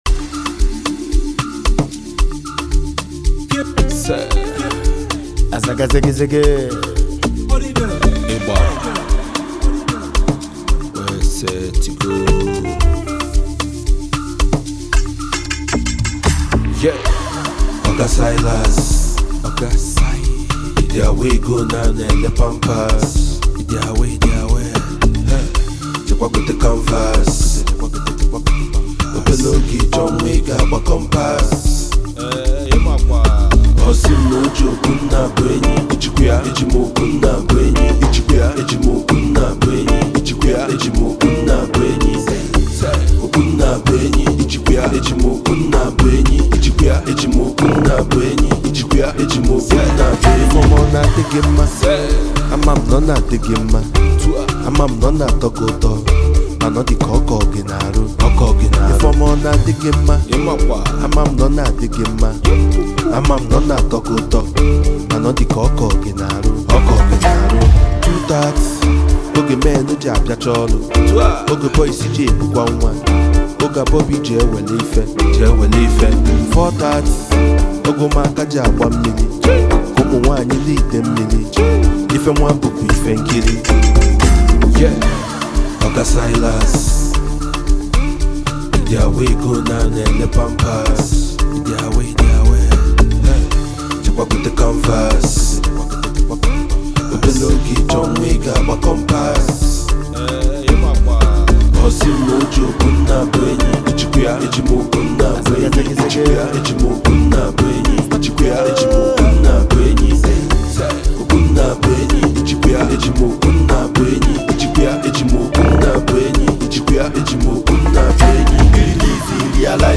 This energetic track
Afrobeat